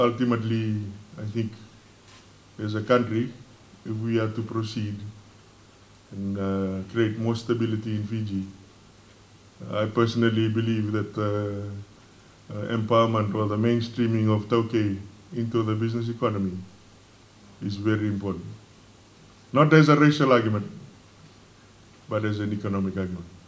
These are the words of the Minister for Trade, Manoa Kamikamica while opening the resource owners symposium held on Monday.